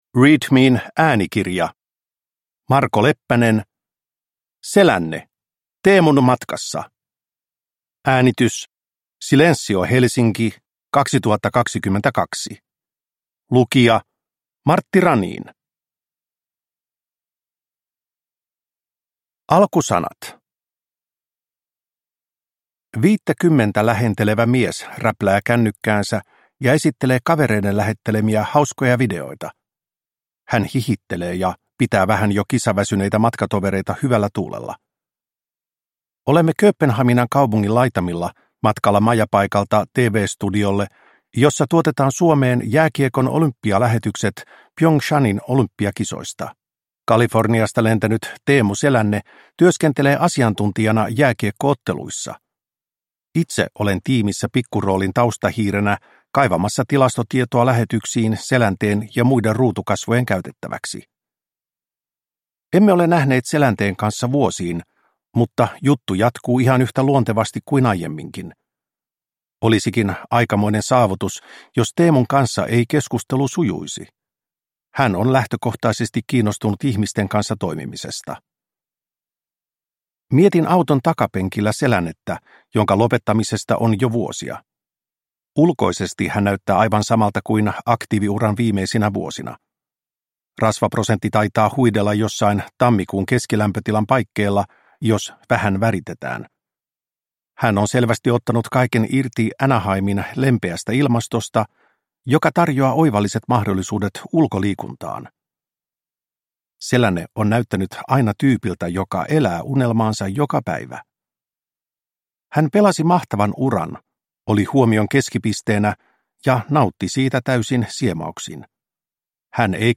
Selänne - Teemun matkassa – Ljudbok